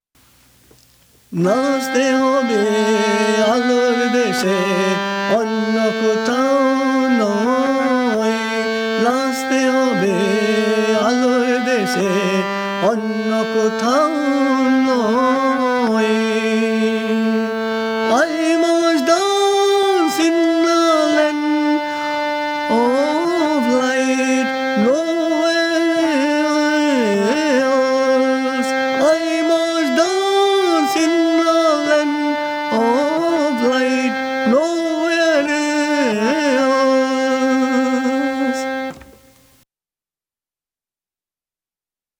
English-Bengali songs
In this recording, he sings all these little jewels.